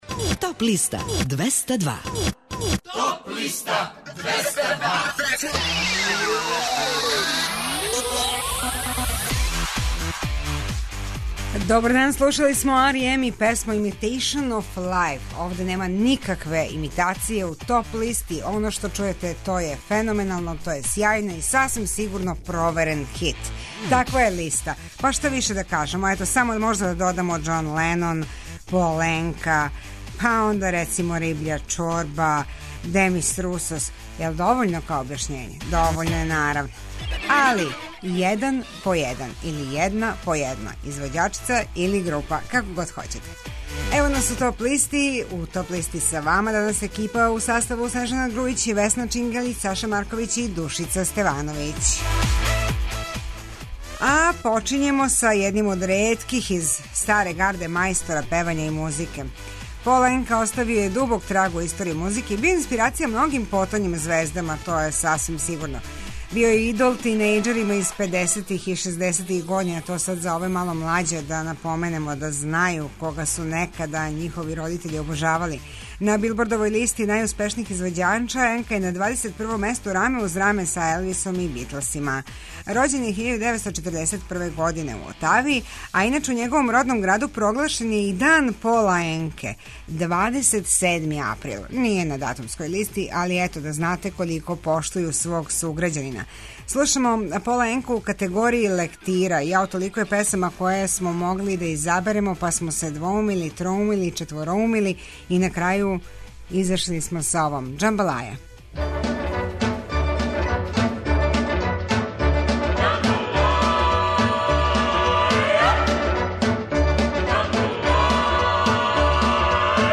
преузми : 25.50 MB Топ листа Autor: Београд 202 Емисија садржи више различитих жанровских подлиста.